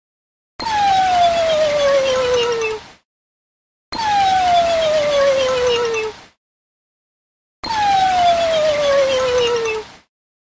Clash Royale Skeleton Crying Sound Button - Free Download & Play